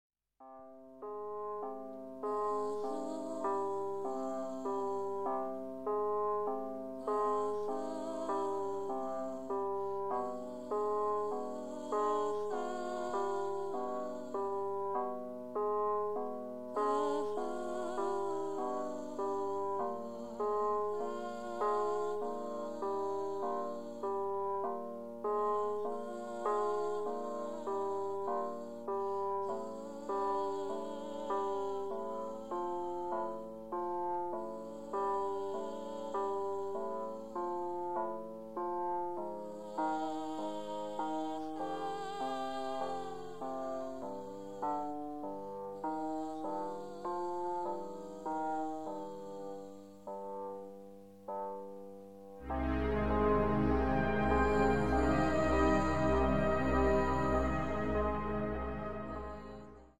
voice, keyboards, electronics, percussion, glox
Completely minimal yet subliminally lush.